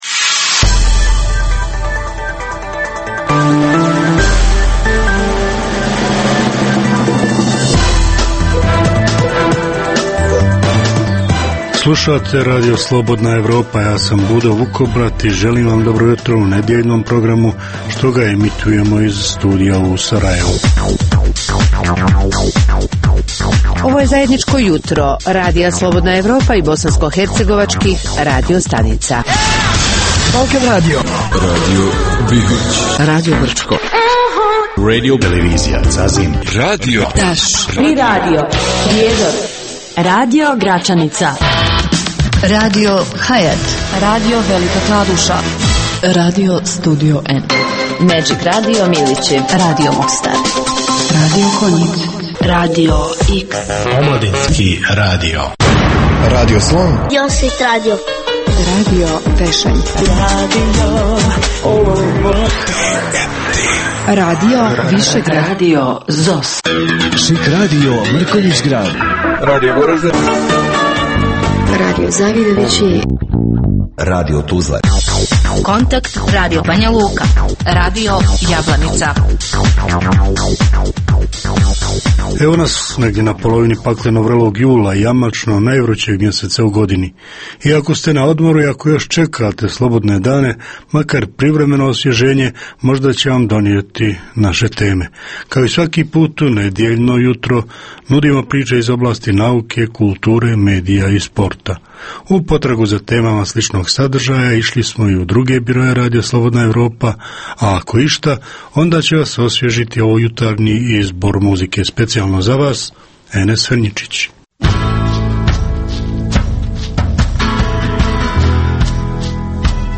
Jutarnji program namijenjen slušaocima u Bosni i Hercegovini.